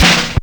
FILLDRUM08-L.wav